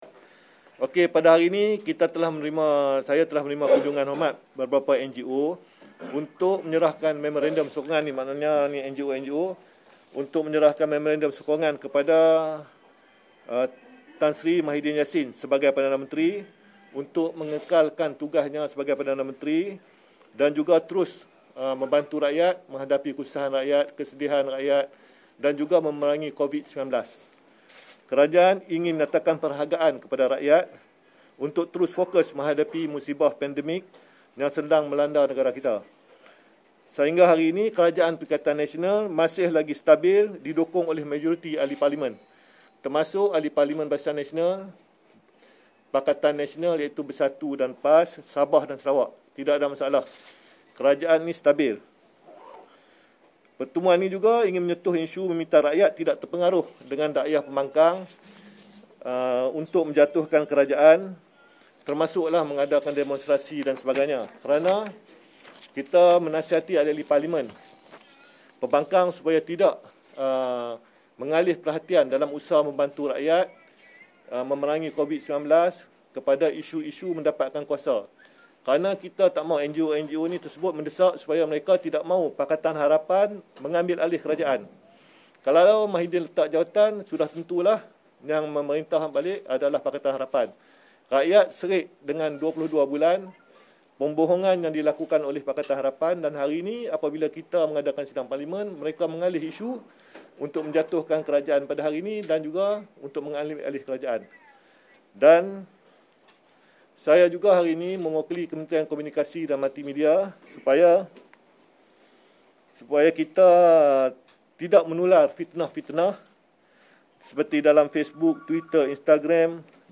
Ikuti sidang media khas oleh Timbalan Menteri Komunikasi dan Multimedia, Datuk Zahidi Zainul Abidin yang juga Ahli Majlis Kerja Tertinggi UMNO.